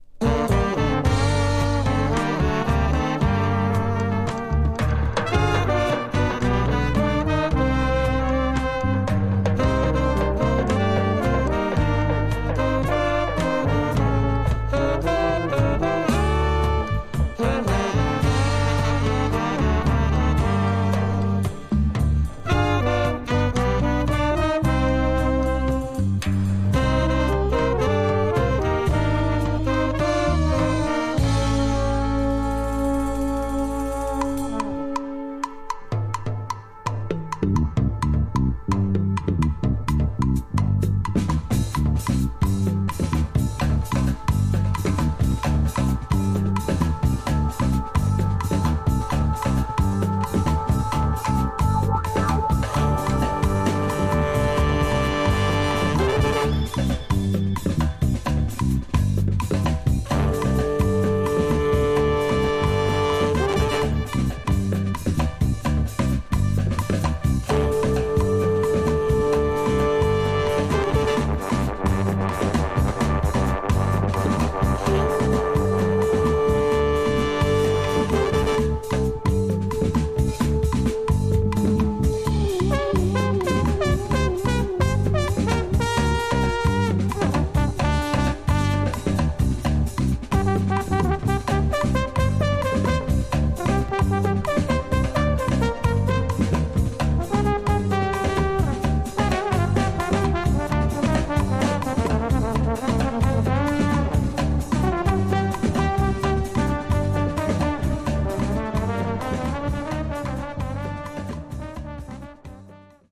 Tags: Brass , Psicodelico , Colombia , Bogotá